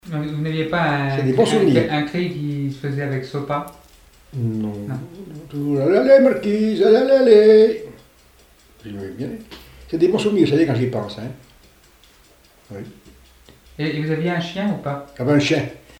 Appel pour les juments
Bouchoux (Les)
Pièce musicale inédite